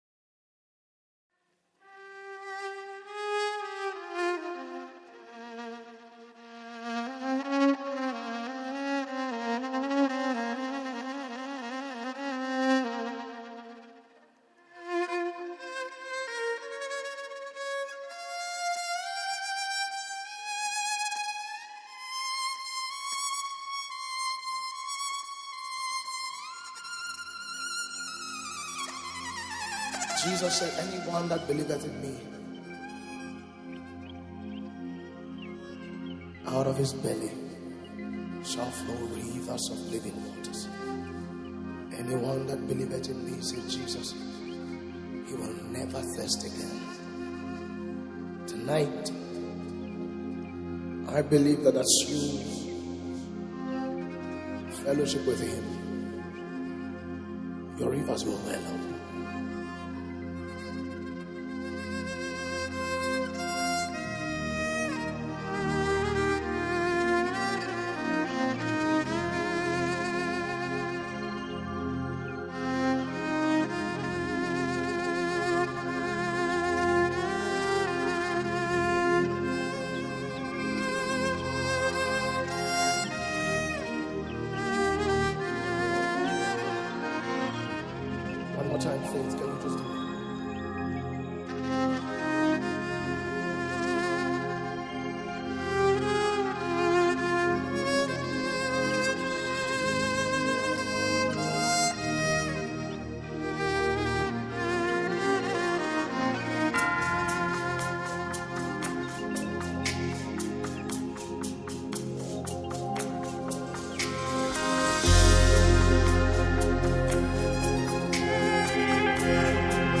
deep worship and revival song